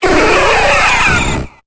Cri de Mewtwo dans Pokémon Épée et Bouclier.